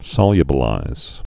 (sŏlyə-bə-līz)